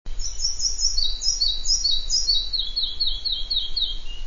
Muchołówka mała - Ficedula parva
fparva.wav